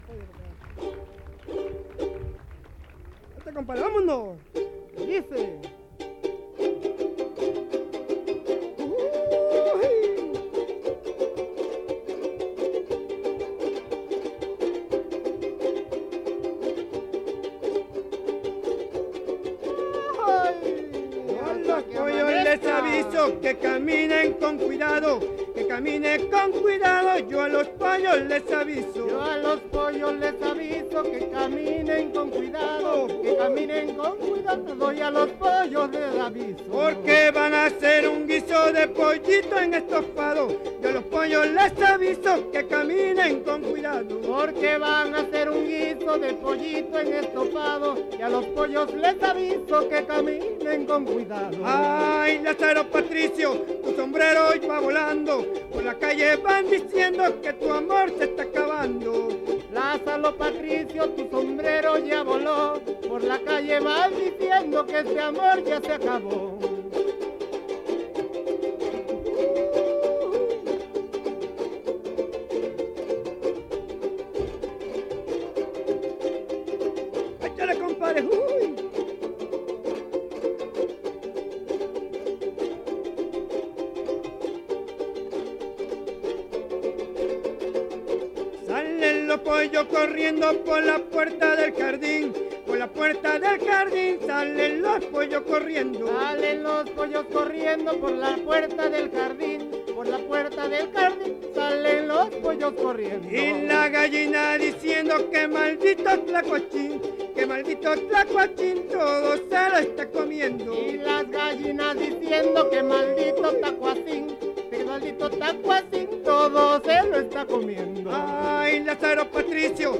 • Los abandonados (Grupo musical)
Encuentro de jaraneros